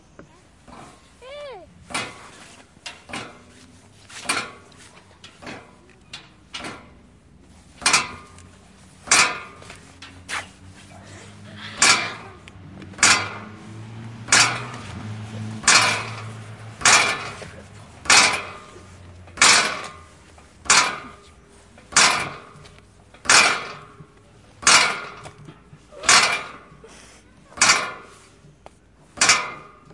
金属门
描述：金属门打开和关闭
Tag: 房子 金属